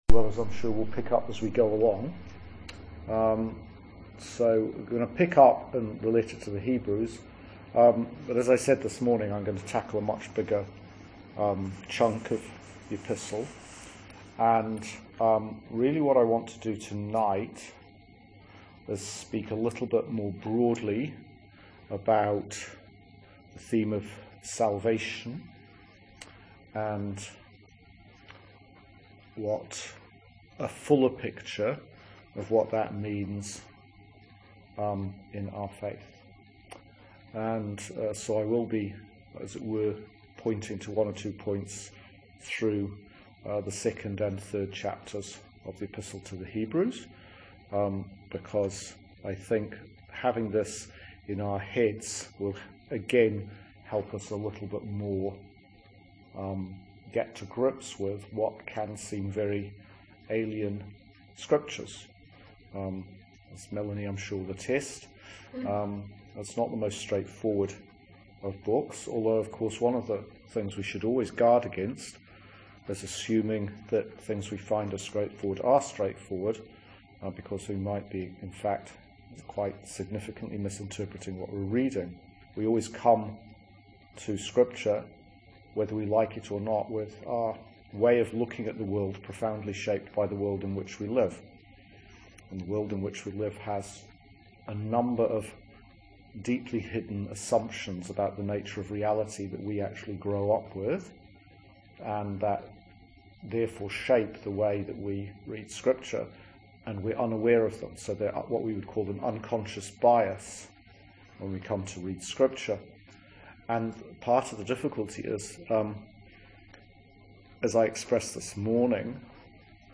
The audio of a talk given in our series on The Epistle to the Hebrews